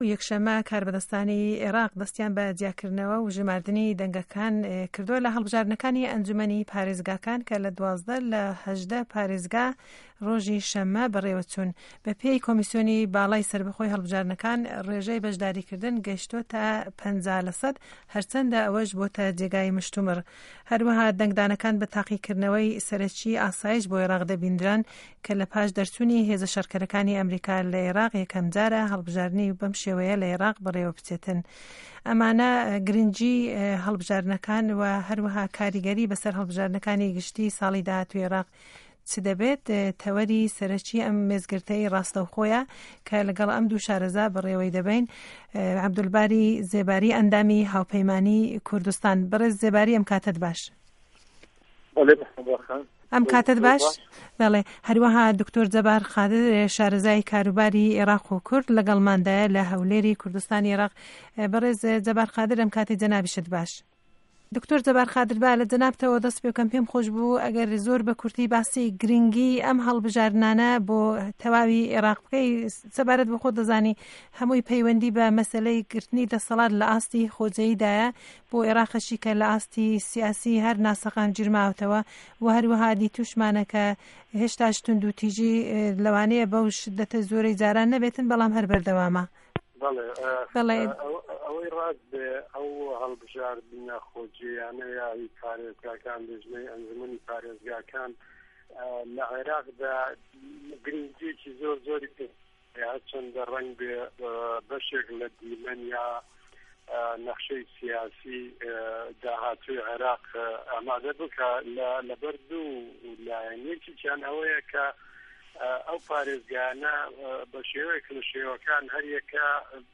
مێزگرد: ڕۆژی پاش هه‌ڵبژاردنه‌کانی عێڕاق